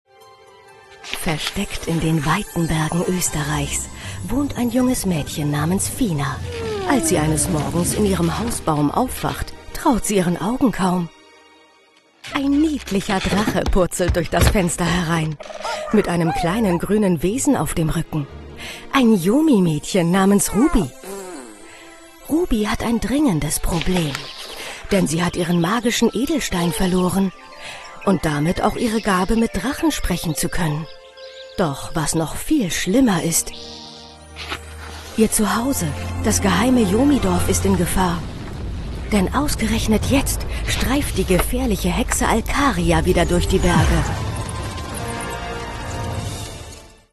hell, fein, zart, sehr variabel
Jung (18-30)
Narrative